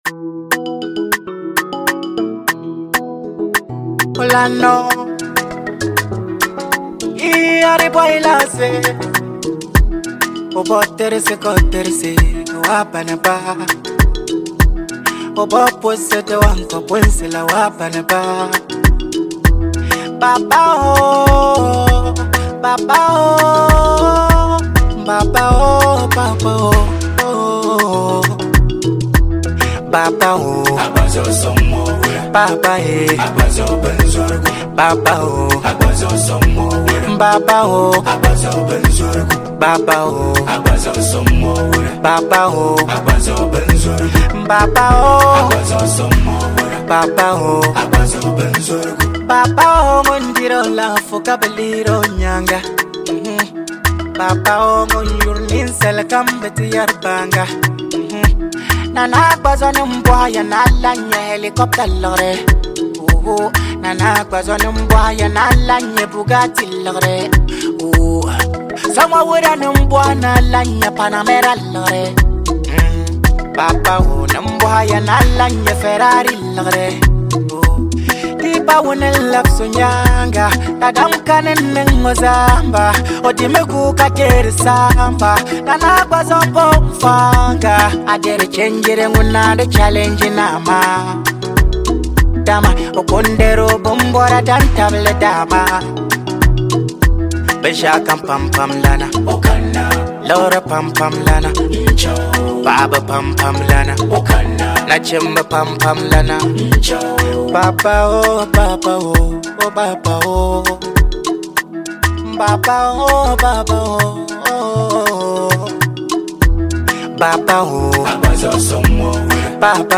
infectious beats
Most of his songs are Afrobeat and Dancehall.